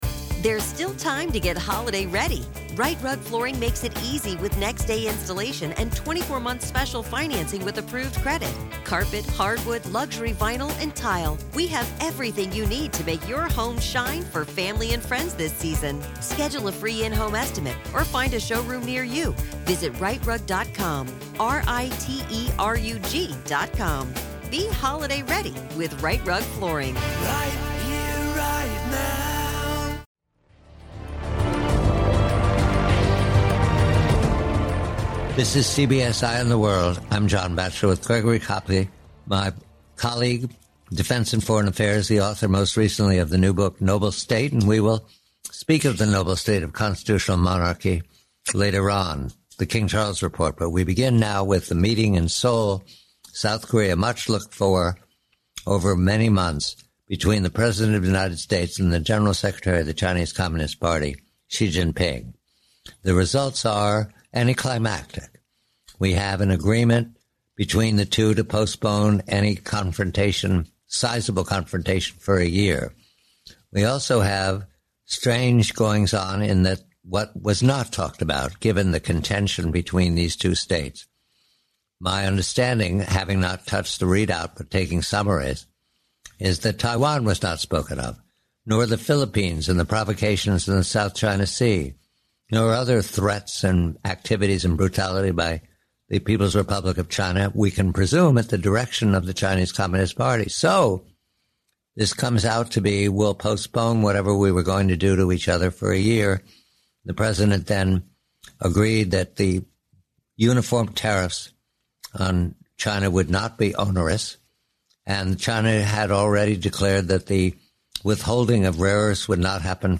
Discussion shifts to the overwhelming US military buildup near Venezuela, which might force Maduro's departure by damaging his credibility, possibly via anti-narcotics action. The interview concludes by analyzing the anticlimactic Trump-Xi meeting, attributing the lack of confrontation to Xi Jinping's significantly weakened position due to China's shattered economy and internal power struggles. 1895 NIGERIA